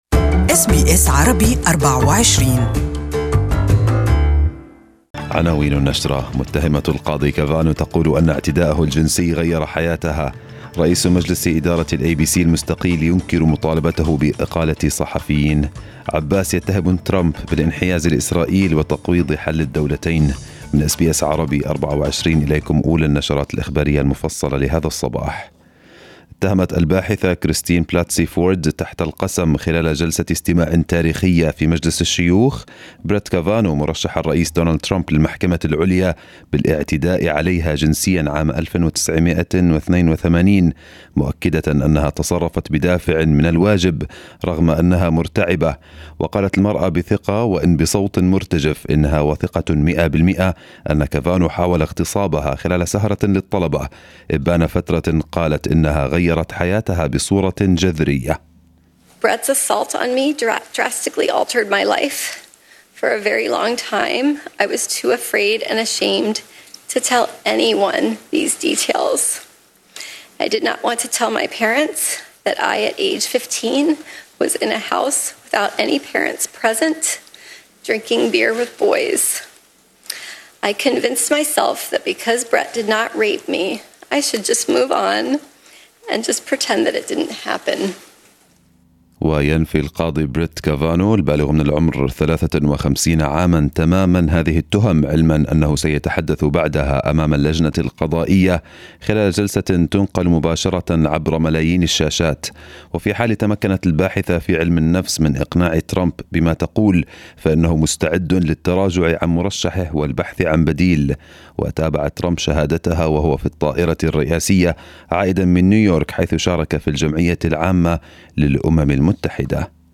First news bulletin in the morning